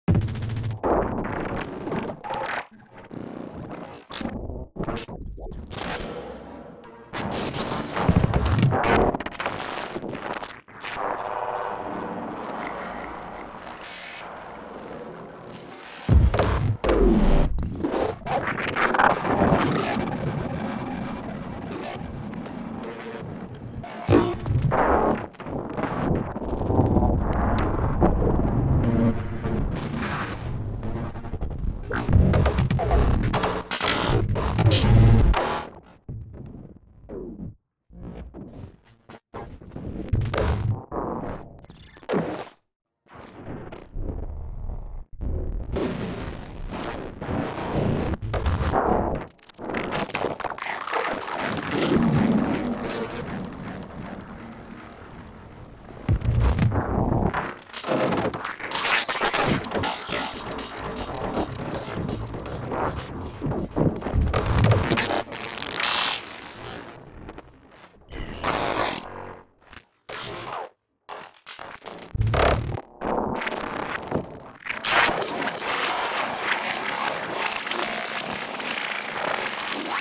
As a contrast to those with more refined tastes than mine, here’s a loop from DrumComputer on the iPad, mangled with randomised bus routing and lots of locks for effects and LFOs.